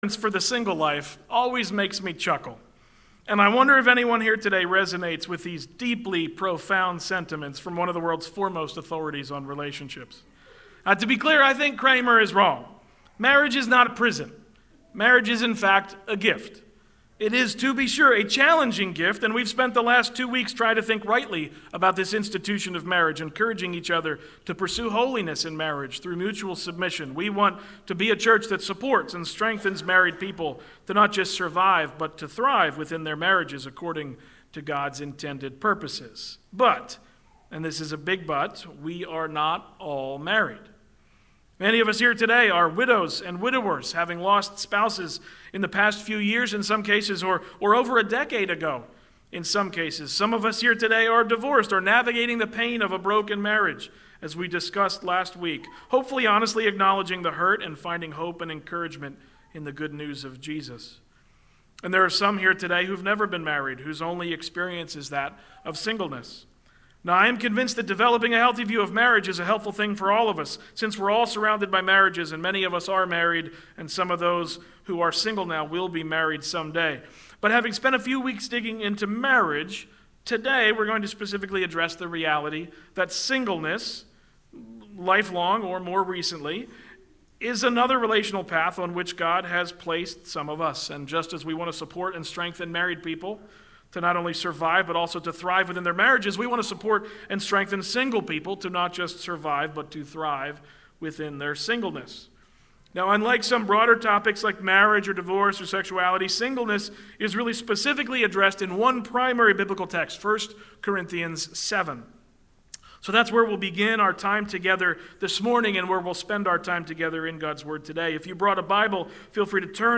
Service Type: Sunday Morning Services